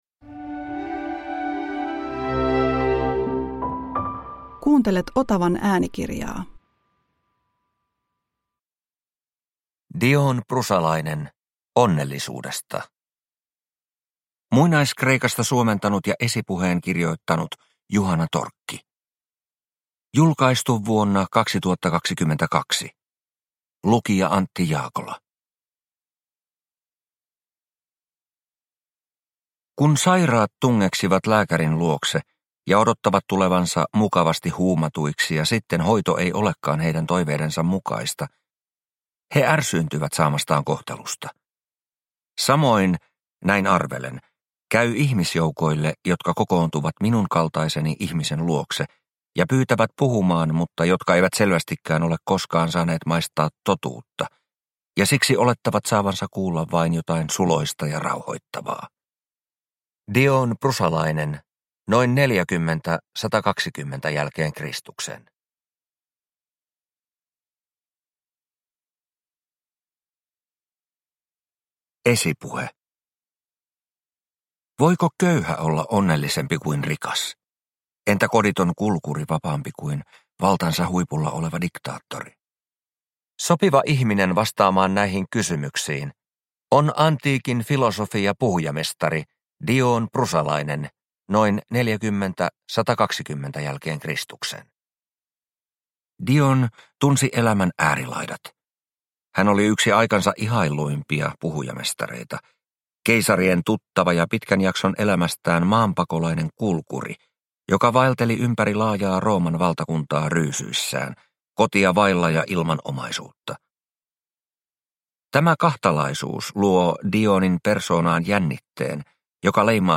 Dion Prusalainen - Onnellisuudesta – Ljudbok – Laddas ner